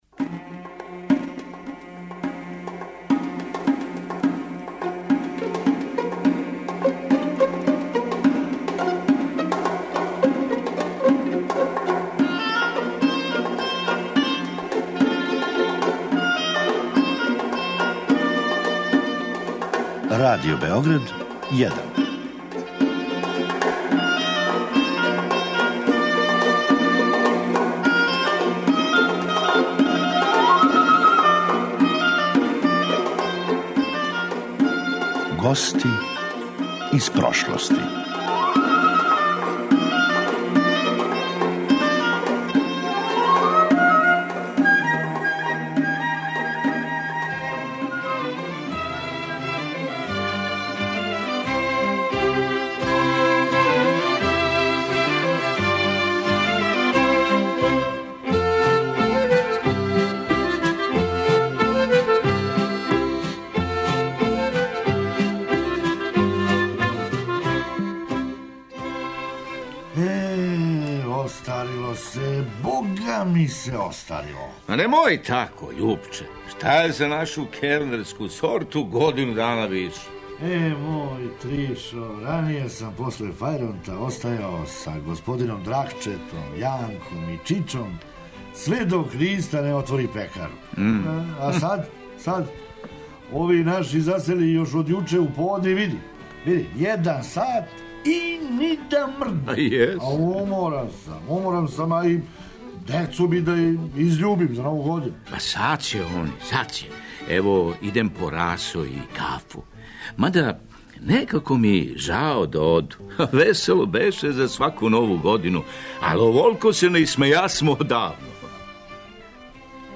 Драмски уметници